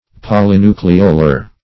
Search Result for " polynucleolar" : The Collaborative International Dictionary of English v.0.48: Polynucleolar \Pol`y*nu*cle"o*lar\, a. [Poly- + nucleolar.]